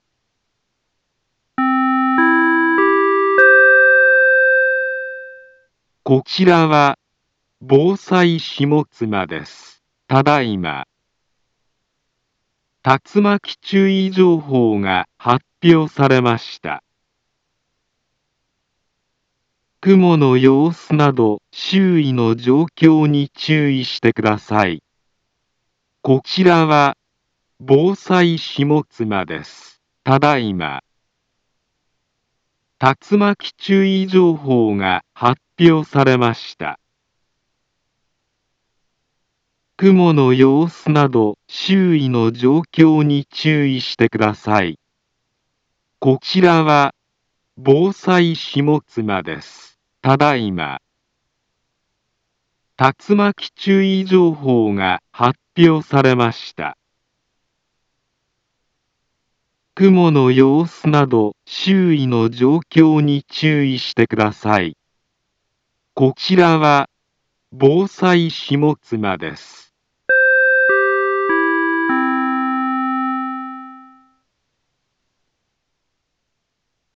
Back Home Ｊアラート情報 音声放送 再生 災害情報 カテゴリ：J-ALERT 登録日時：2022-11-23 21:04:49 インフォメーション：茨城県南部は、竜巻などの激しい突風が発生しやすい気象状況になっています。